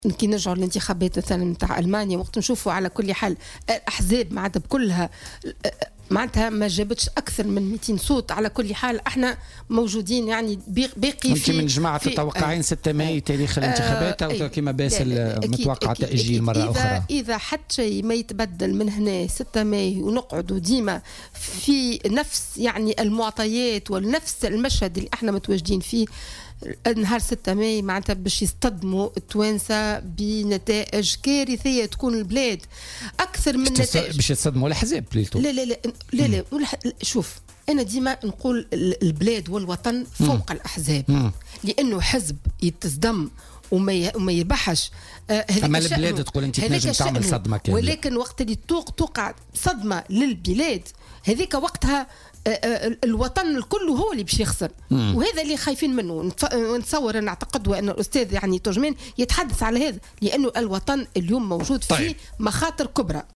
وأضافت الشتاوي ضيفة "بوليتيكا" اليوم الأربعاء 20 ديسمبر 2017، أن نتائج الانتخابات البلدية ستكون كارثية أكثر من نتائج الانتخابات الجزئية بألمانيا، وستمثّل صدمة للشعب التونسي.